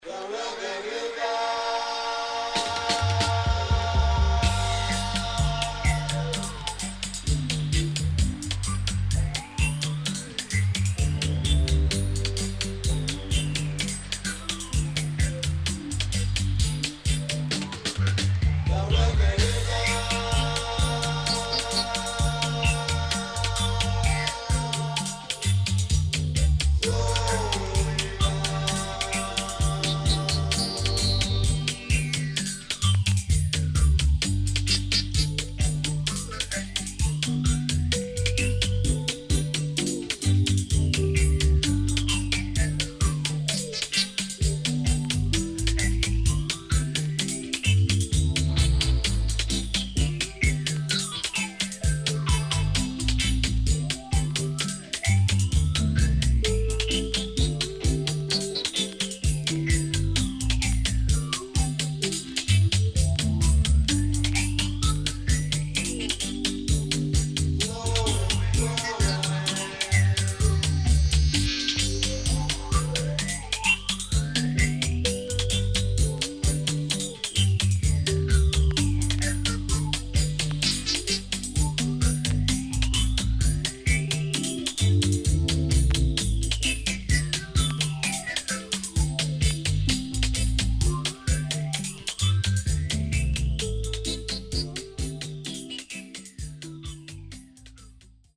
SIDE B:少しチリノイズ、プチノイズ入ります。